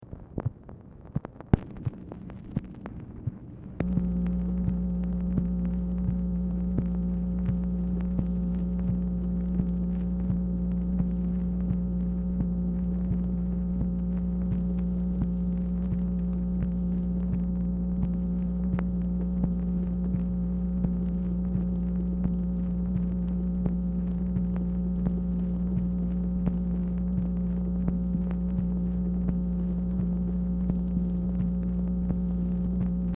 Telephone conversation # 10758, sound recording, MACHINE NOISE, 9/13/1966, time unknown | Discover LBJ
Format Dictation belt